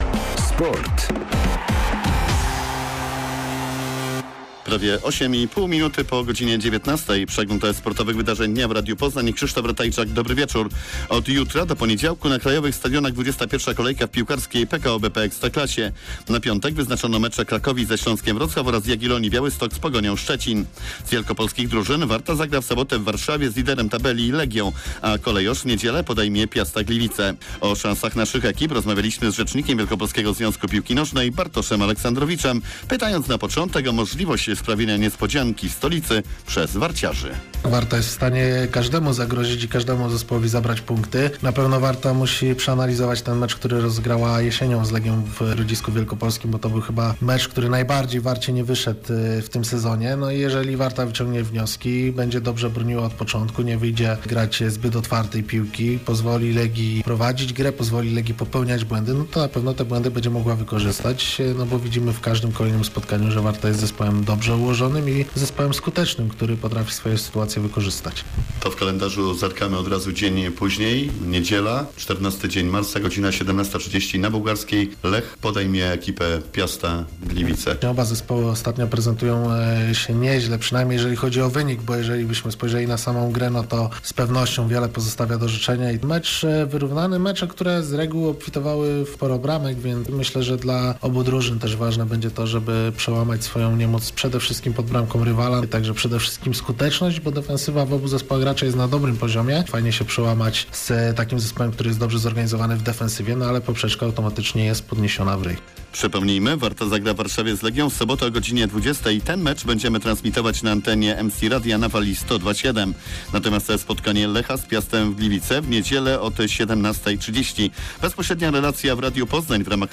11.03.2021 SERWIS SPORTOWY GODZ. 19:05